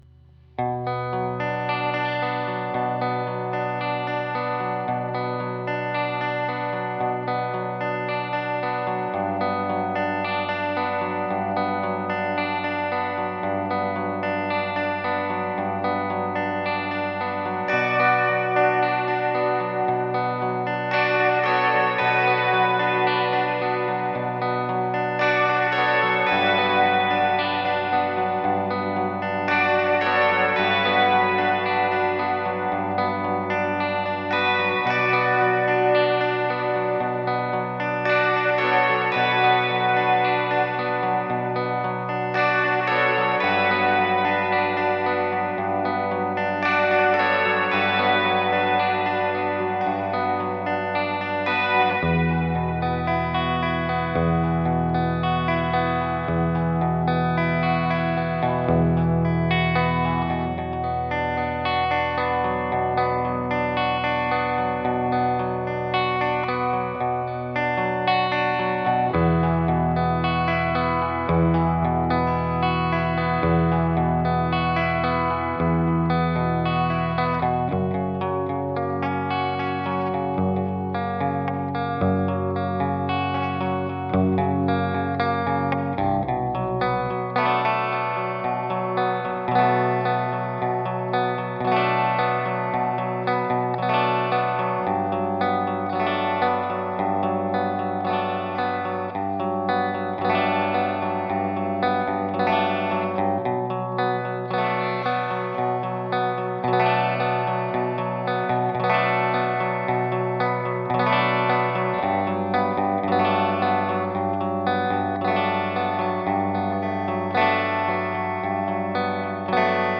Genre: Depressive Black Metal